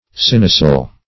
Search Result for " synocil" : The Collaborative International Dictionary of English v.0.48: Synocil \Syn"o*cil\, n. [Pref. syn- + cilium.]